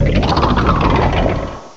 cry_not_dhelmise.aif